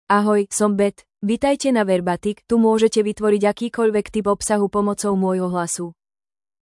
Beth — Female Slovak (Slovakia) AI Voice | TTS, Voice Cloning & Video | Verbatik AI
FemaleSlovak (Slovakia)
BethFemale Slovak AI voice
Beth is a female AI voice for Slovak (Slovakia).
Voice sample
Listen to Beth's female Slovak voice.
Beth delivers clear pronunciation with authentic Slovakia Slovak intonation, making your content sound professionally produced.